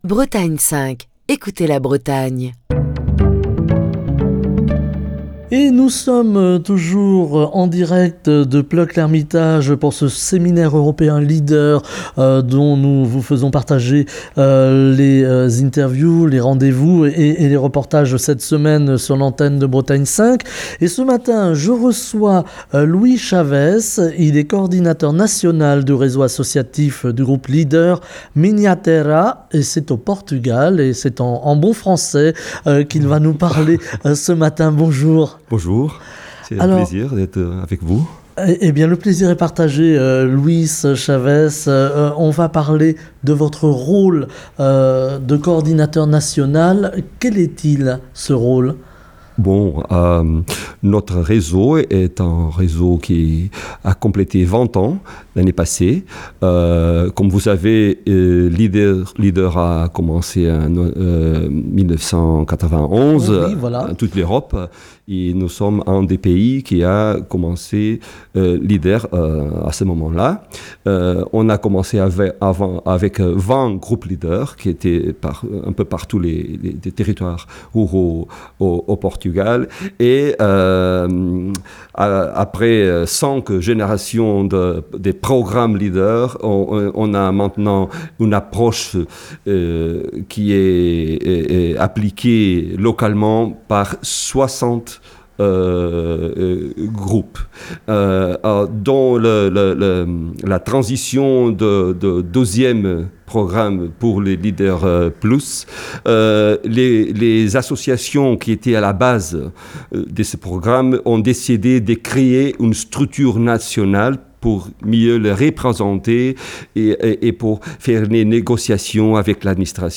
Semaine Europe et ruralité - Bretagne 5 est en direct de Plœuc-L'Hermitage pour le séminaire LEADER.